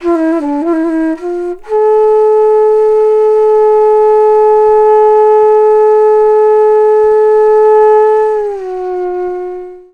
FLUTE-B08 -R.wav